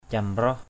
/ca-mrɔh/ (t.) quá khích. hu biniai camraoh h~% b|=n` c_m<H có thái độ quá khích.